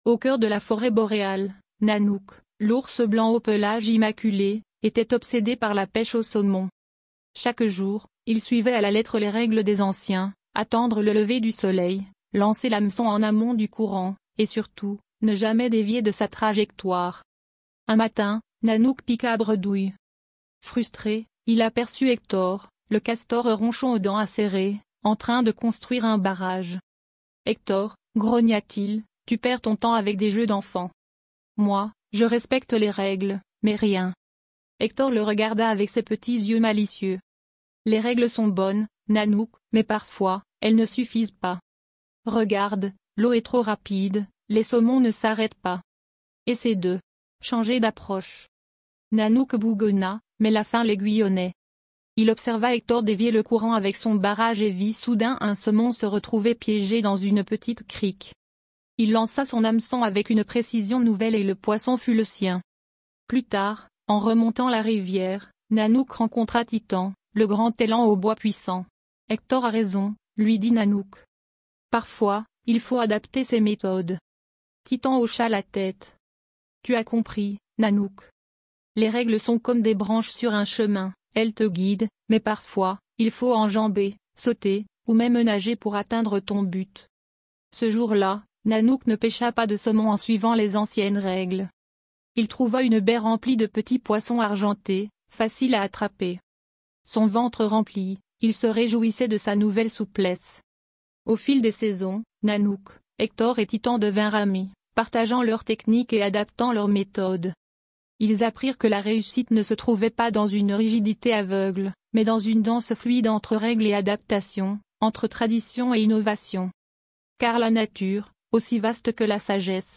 Nanuk, Hector et Titan - Conte canadien